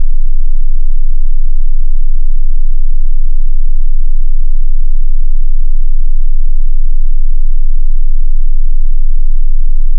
AudioCheck Tone Tests
Low Frequency Tones